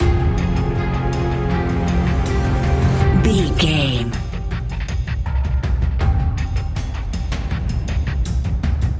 Scary Horror Hybrid Industrial Suspense Stinger.
Aeolian/Minor
ominous
haunting
eerie
strings
drums
percussion
horror music